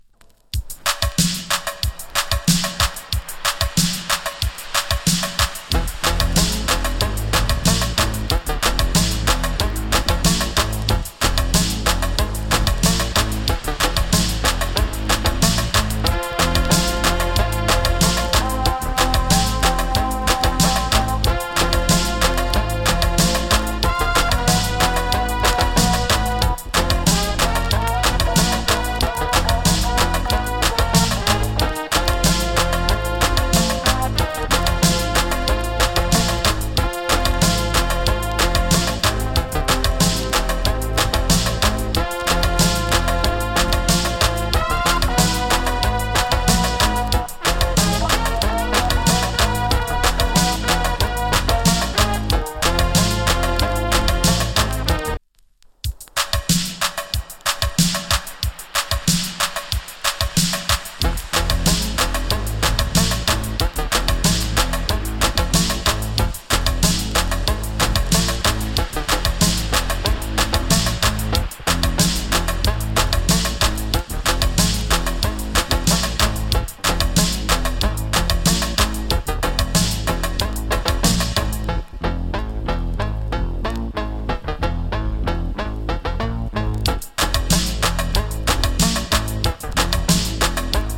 FINE COVER INST !